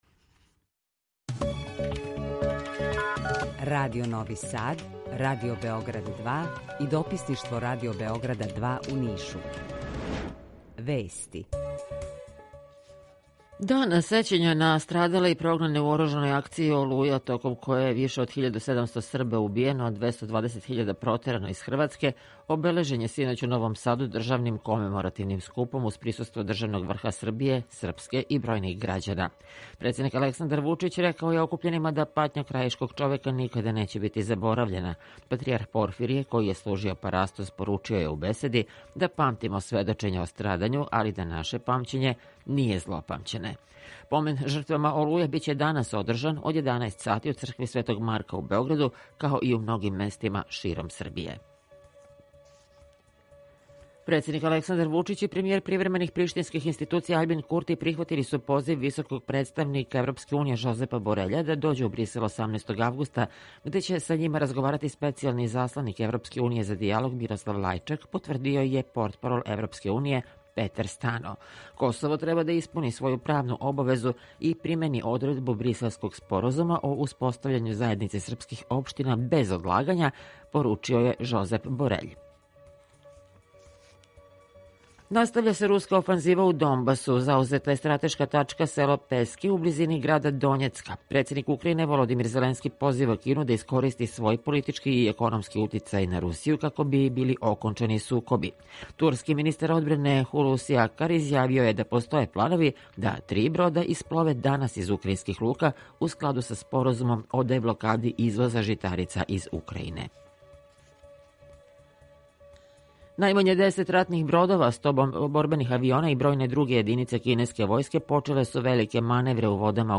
Емисију реализујемо заједно са студиом Радија Републике Српске у Бањалуци и са Радио Новим Садом.
Јутарњи програм из три студија
У два сата, ту је и добра музика, другачија у односу на остале радио-станице.